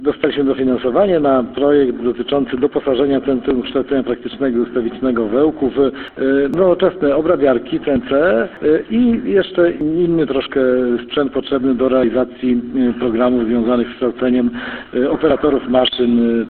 O tym, na co zostanie przeznaczone dofinansowanie rzędu 700 tysięcy złotych, mówi Marek Chojnowski, starosta powiatu ełckiego.